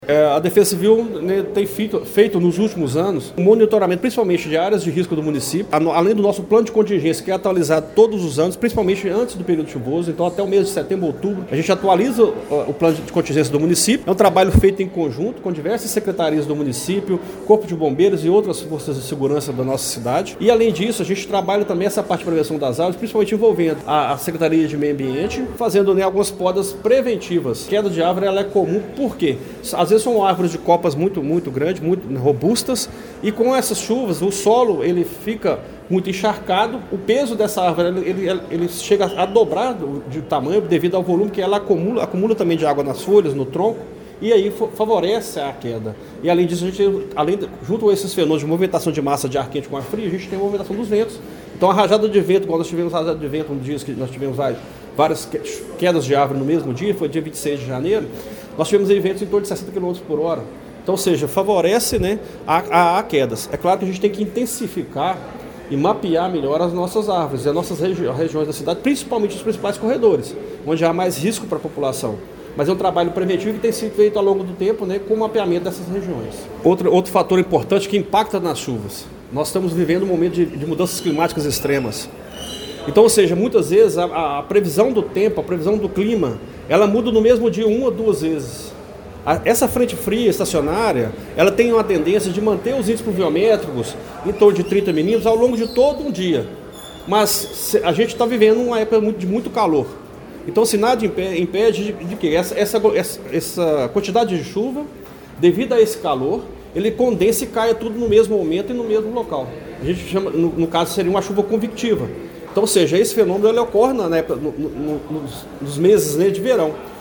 O coordenador de Proteção e Defesa Civil, Edson Cecílio da Silva, apresentou uma análise detalhada do cenário climático esperado para os próximos dias em Pará de Minas, durante coletiva de imprensa realizada na tarde de ontem (24).